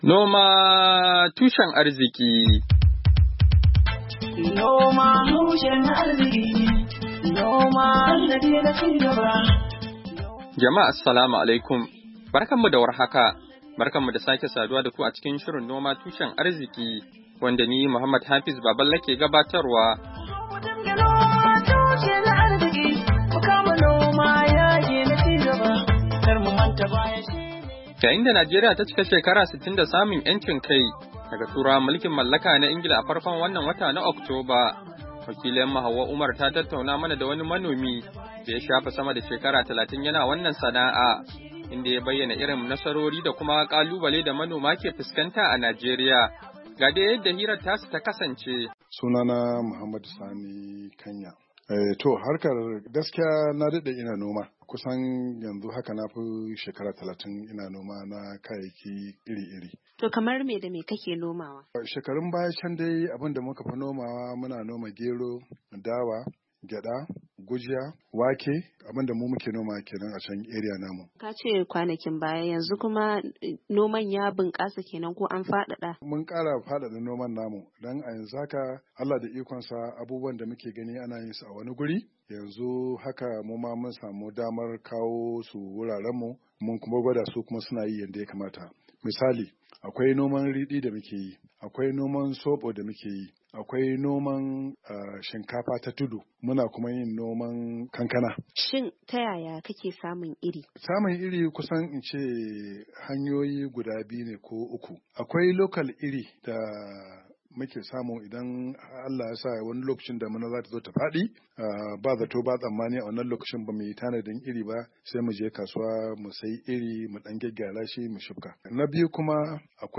Hira Da Manomi Kan Irin Kalubalen Da suke Fuskanta a Najeriya - 6'15